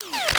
Added more sound effects.
MOTRSrvo_Plasma Rifle Disarm_01_SFRMS_SCIWPNS.wav